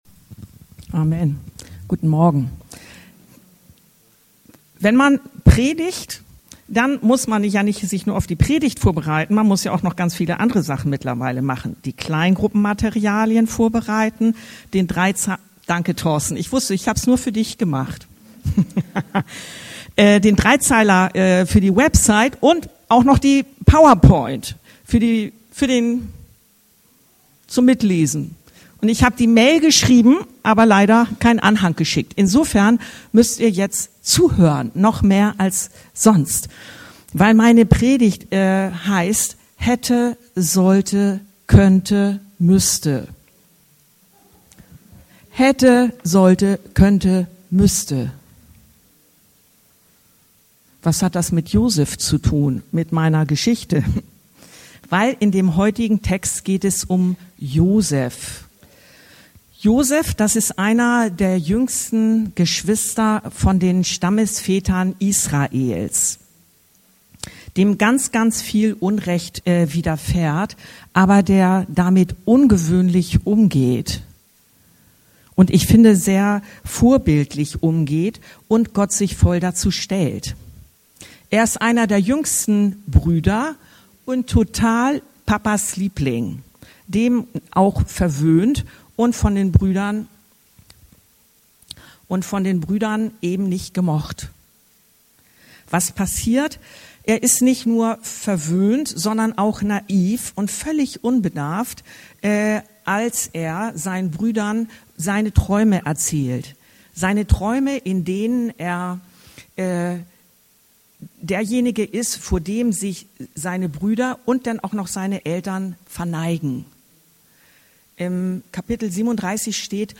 Hätte, sollte, könnte, müsste… - 1.Mo. 45,5-8 ~ Anskar-Kirche Hamburg- Predigten Podcast